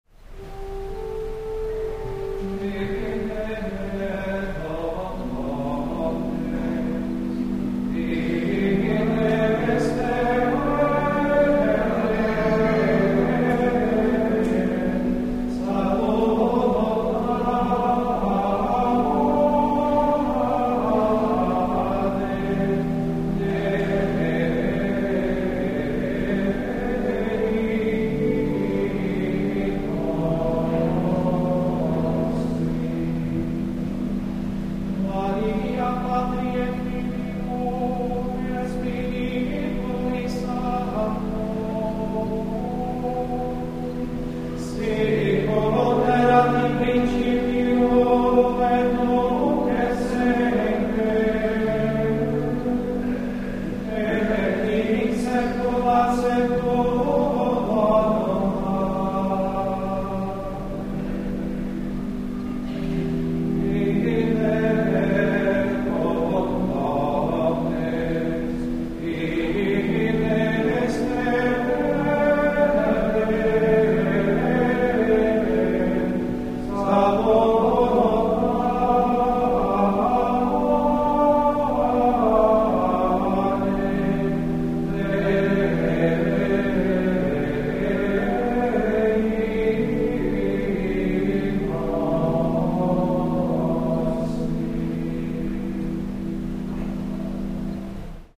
Chant Notation A second example is shown here of the chant Viderunt omnes , which later became the basis for Perotin's monumental four-part organa for the Feast of St. Stephen in 1198(?).